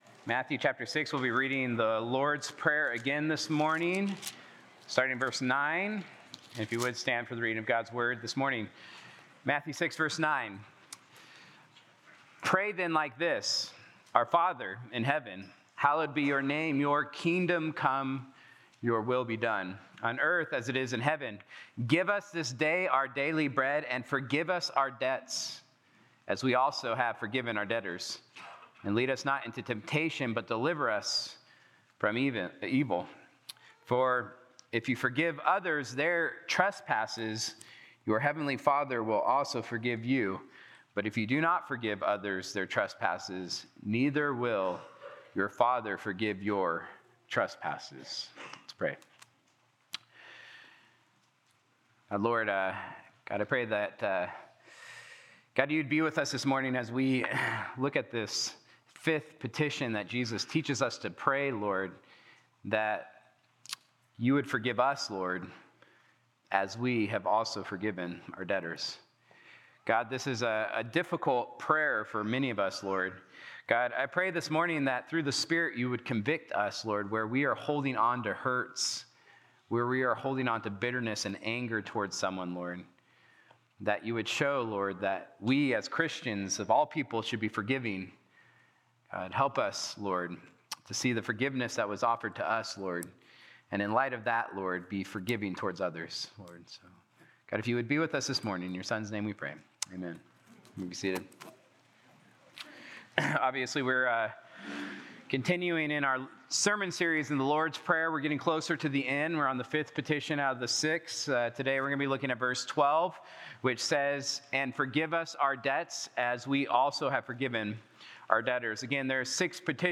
Sunday-Sermon-March-1-2026.mp3